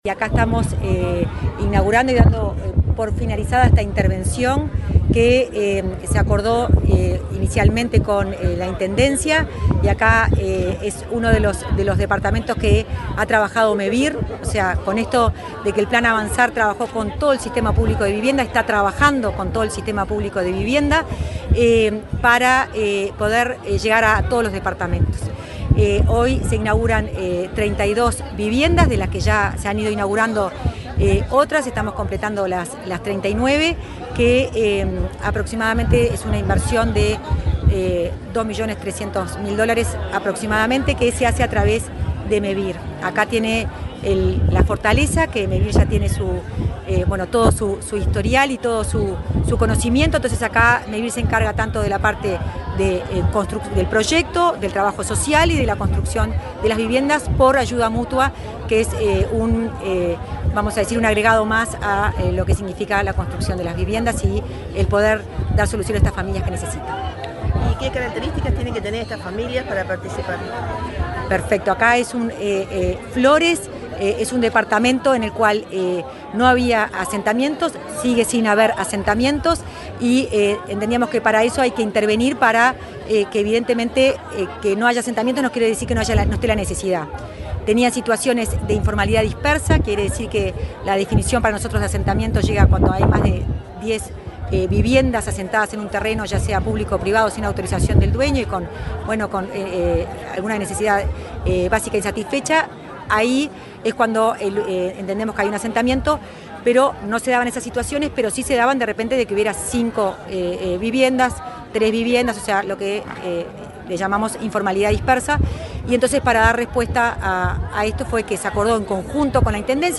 Declaraciones de la directora de Integración Social y Urbana
Declaraciones de la directora de Integración Social y Urbana 28/11/2024 Compartir Facebook X Copiar enlace WhatsApp LinkedIn La directora nacional de Integración Social y Urbana, Florencia Arbeleche, dialogó con la prensa, durante la inauguración de 32 viviendas del Plan Avanzar en la ciudad de Trinidad, departamento de Flores.